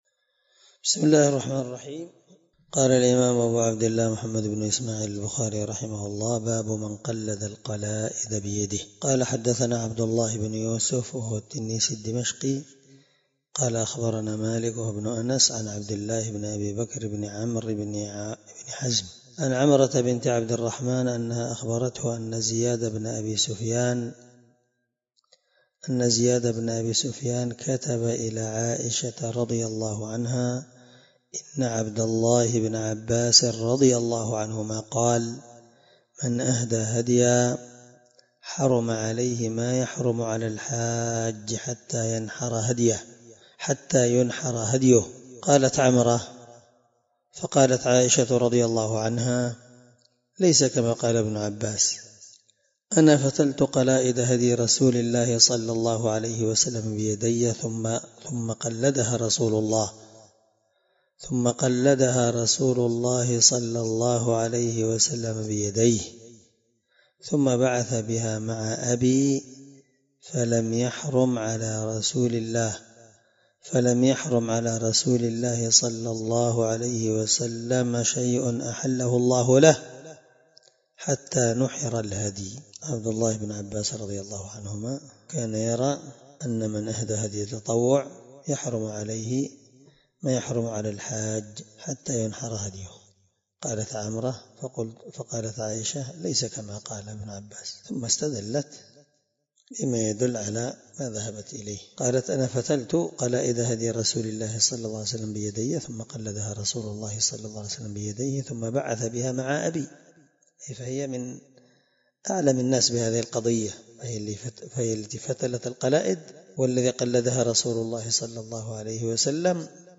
الدرس74 من شرح كتاب الحج حديث رقم(1700-1706 )من صحيح البخاري